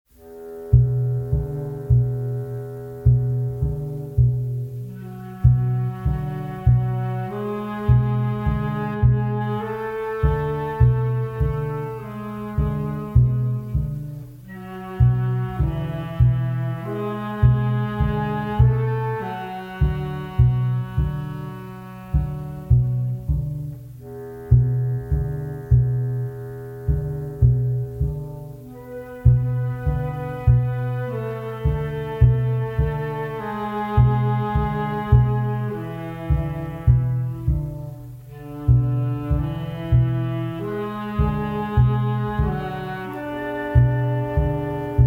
minimal, obsessive style
in clean monaural sound